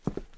Footstep Concrete Walking 1_05.wav